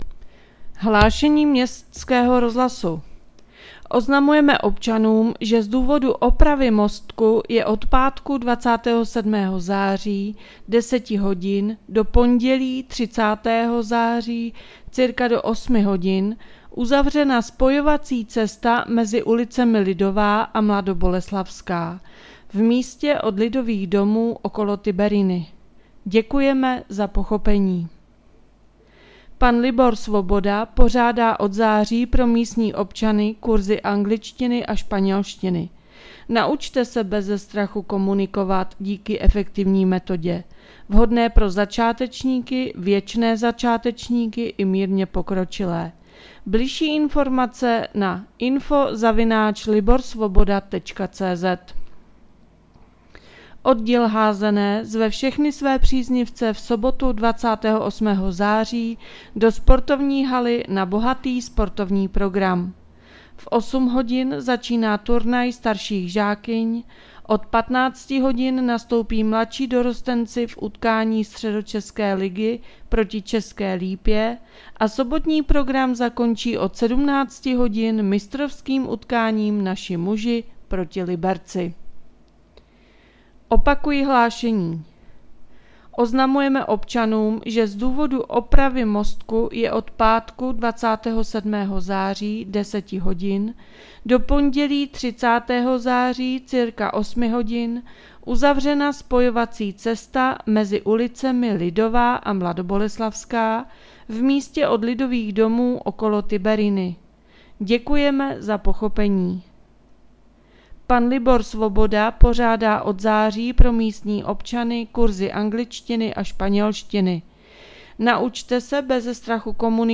Hlášení městského rozhlasu 27.9.2024